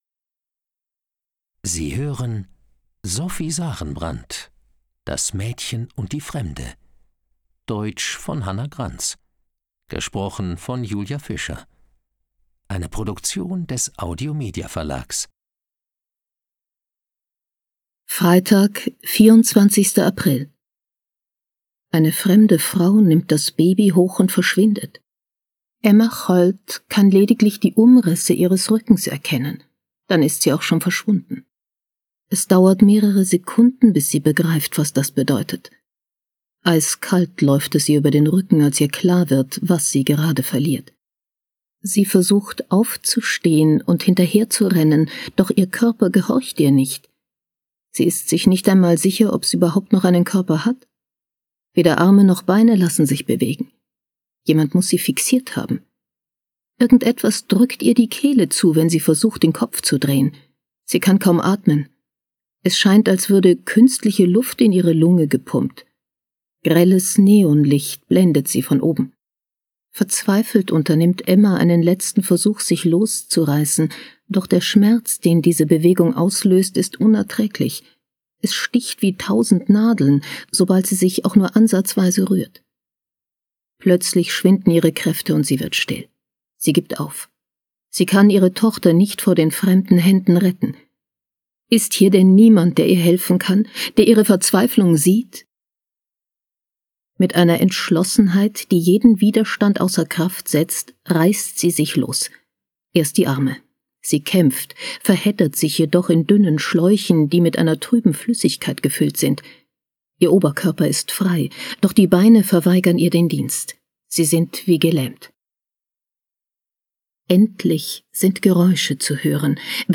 Schlagworte Baby • Bedrohung • Erinnerung • Ermittlerin • Gedächtnisverlust • Hörbuch; Krimis/Thriller-Lesung • Koma • Kommissarin • Schweden • Skandinavien • Tochter • Unfall